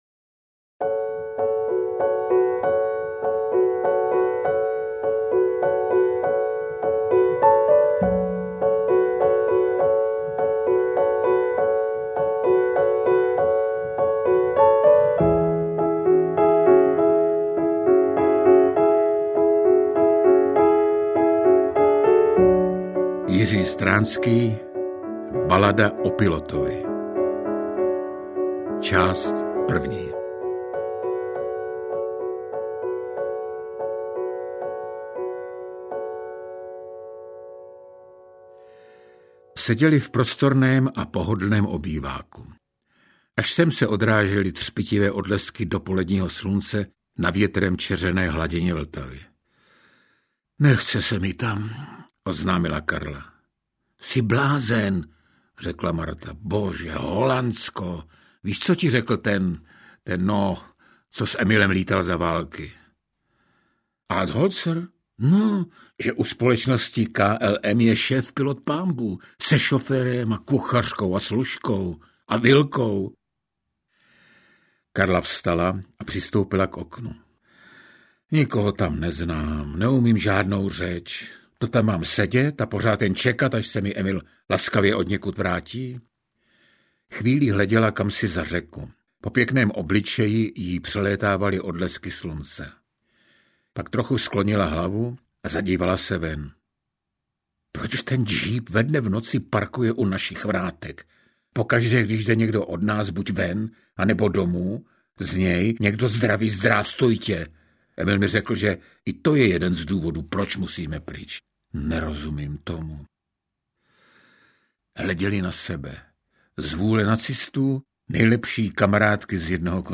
Interpret:  Luděk Munzar
AudioKniha ke stažení, 6 x mp3, délka 1 hod. 42 min., velikost 93,5 MB, česky